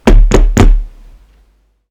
Polaris/sound/effects/shieldbash.ogg
shieldbash.ogg